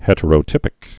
(hĕtə-rō-tĭpĭk) also het·er·o·typ·i·cal (-ĭ-kəl)